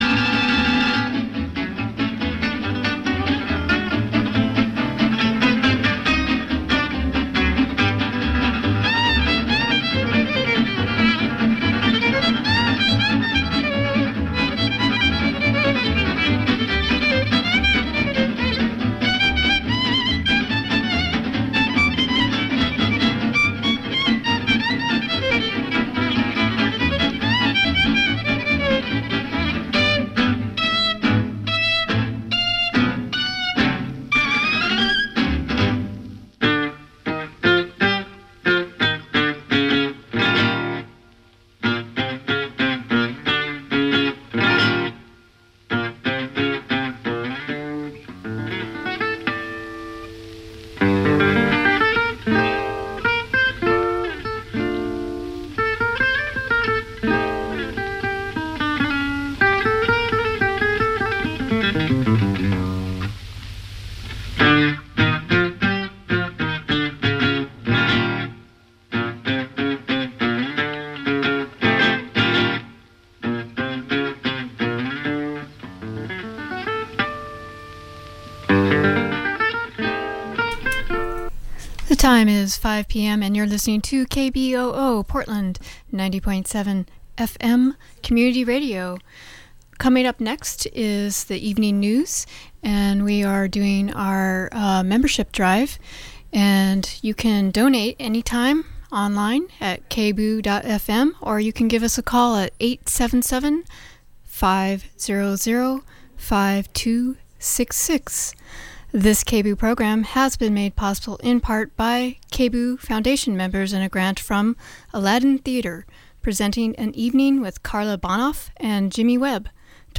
Non-corporate, volunteer-powered, local, national, and international news